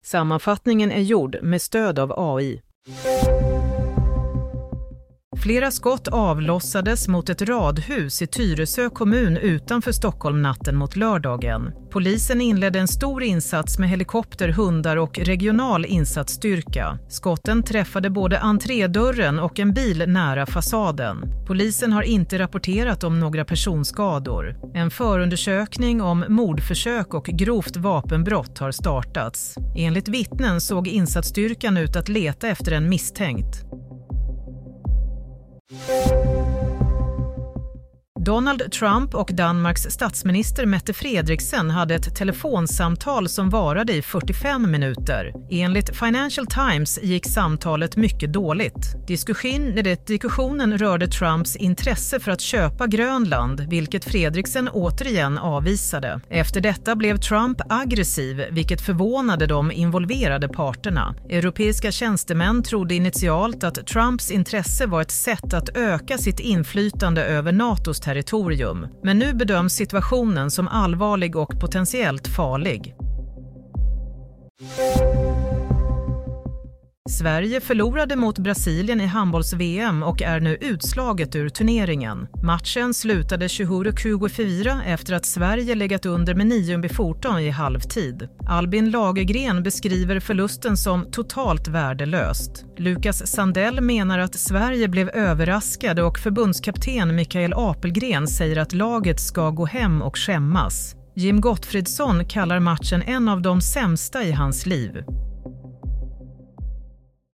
Nyhetssammanfattning – 25 januari 07.30